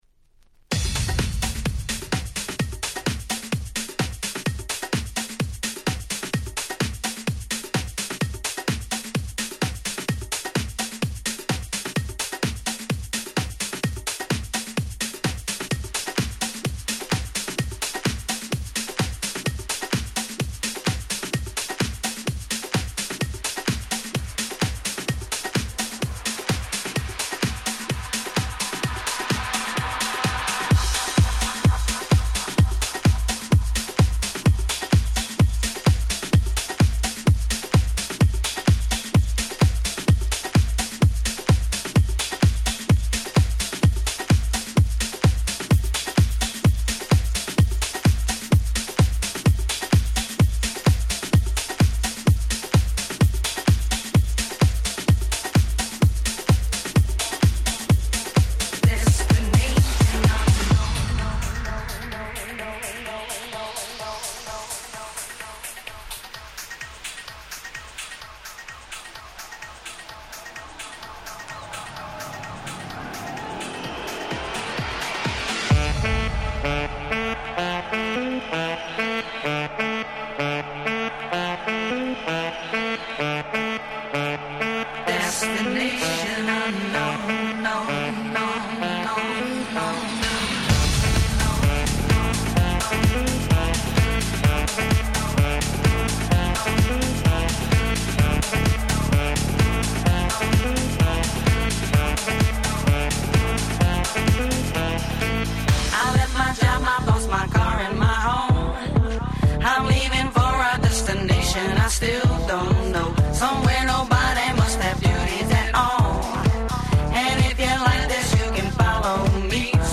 07' Super Hit House/EDM !!
ハウス EDM